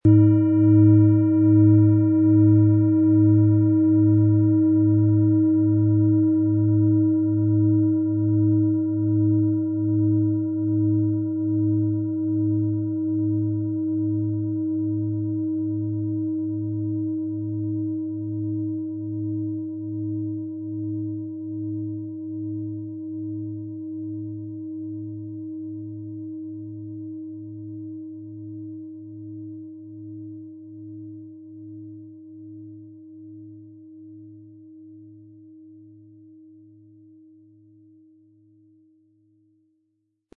Planetenton
Diese tibetanische Neptun Planetenschale kommt aus einer kleinen und feinen Manufaktur in Indien.
Unter dem Artikel-Bild finden Sie den Original-Klang dieser Schale im Audio-Player - Jetzt reinhören.
Im Preis enthalten ist ein passender Klöppel, der die Töne der Schale schön zum Schwingen bringt.
MaterialBronze